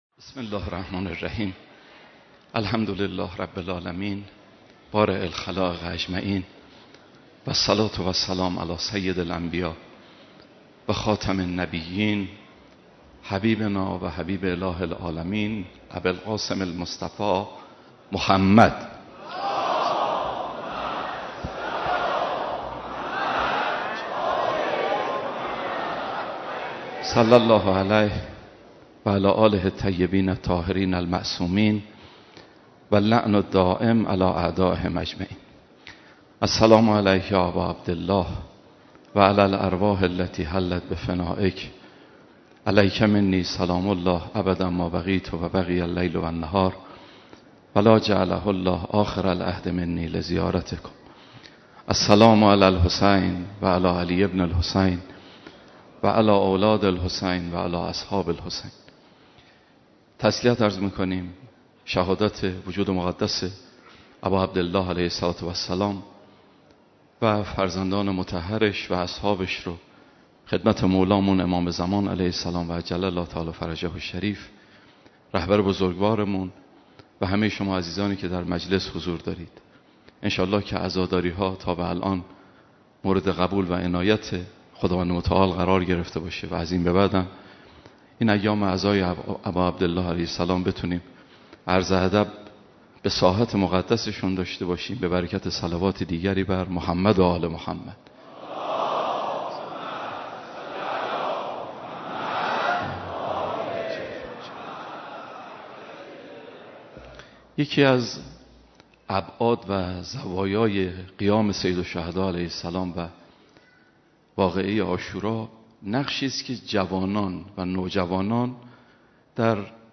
در دومین شب مراسم عزاداری سید و سالار شهیدان حضرت اباعبدالله الحسین(ع) که با حضور رهبر معظم انقلاب در حسینیه امام خمینی(ره) برگزار شد